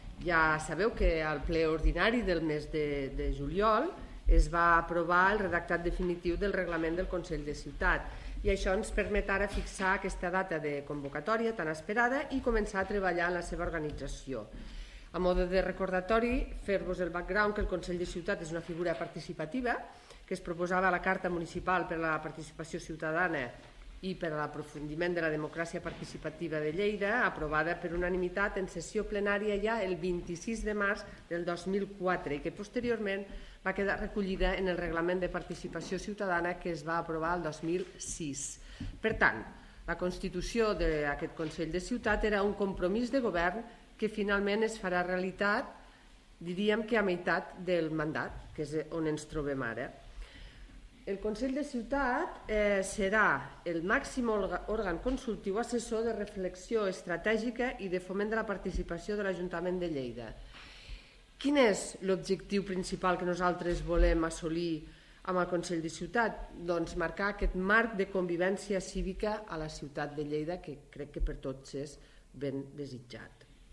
Fitxers relacionats Tall de veu de la regidora Marta Gispert sobre la constitució del Consell de Ciutat Lleida (666.6 KB) Inici de la fase d'inscripció al Consell de Ciutat Lleida - informació (2.4 MB)
tall-de-veu-de-la-regidora-marta-gispert-sobre-la-constitucio-del-consell-de-ciutat-lleida